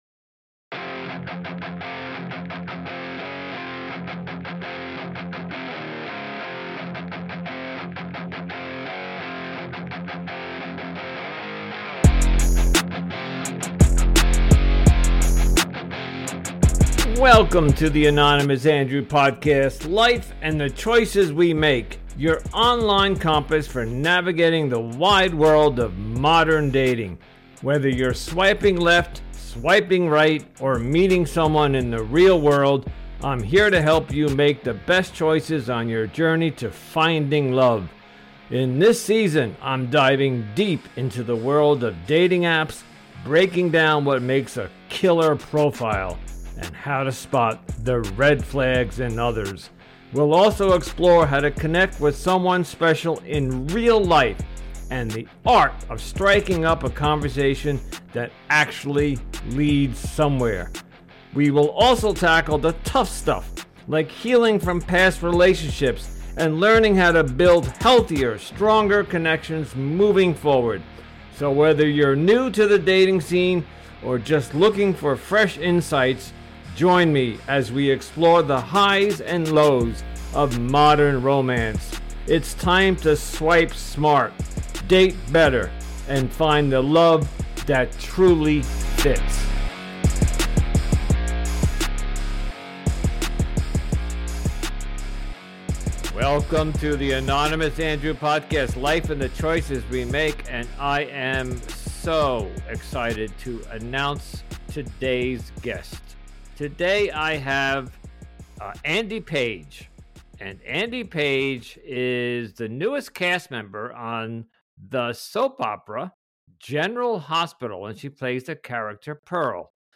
A chat about dating!